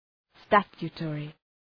Προφορά
{‘stætʃʋ,tɔ:rı}